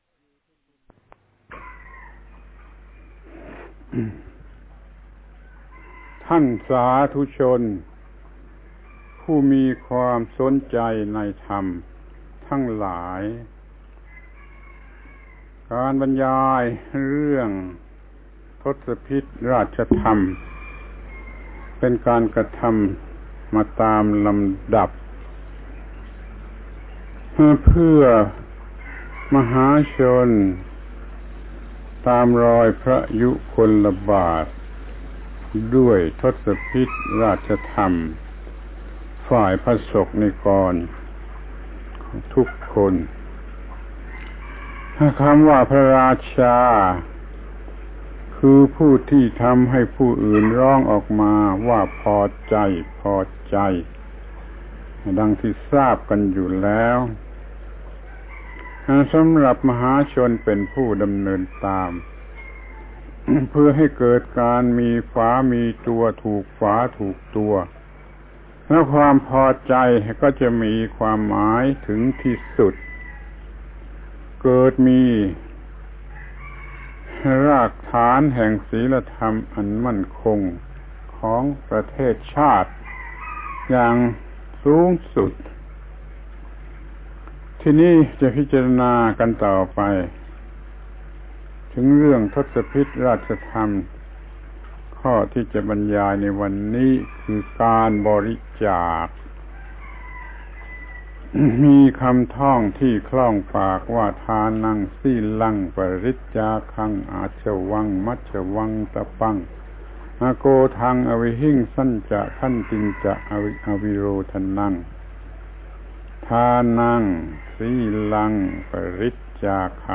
ปาฐกถาธรรมทางวิทยุ ฯ ทศพิธราชธรรม ทศพิธราชธรรมตามรอยพระยุคลบาทด้วยบริจาค